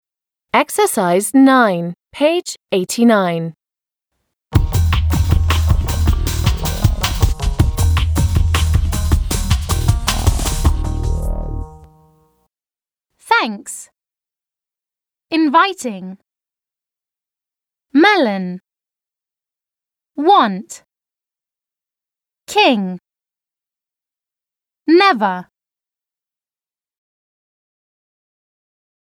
/ŋ/
/n/